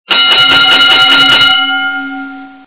bell_multiple.wav